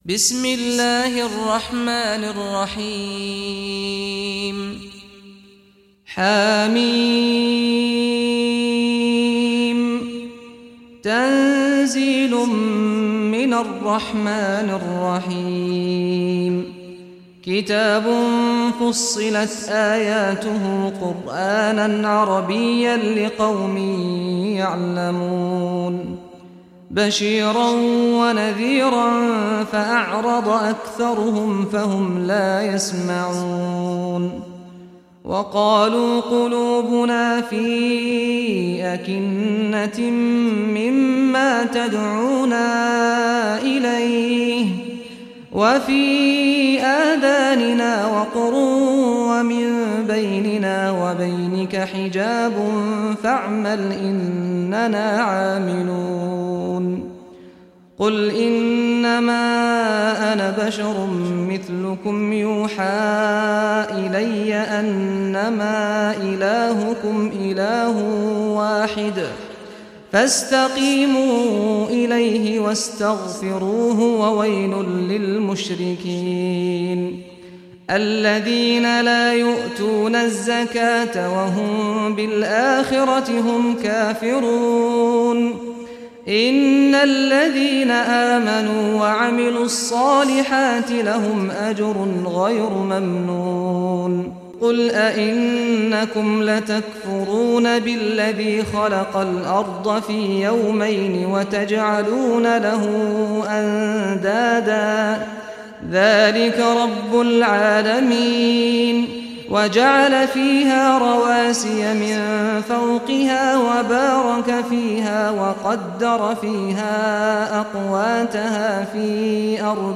Surah Fussilat Recitation by Sheikh Saad al Ghamdi
Surah Fussilat, listen or play online mp3 tilawat / recitation in Arabic in the beautiful voice of Sheikh Saad al Ghamdi.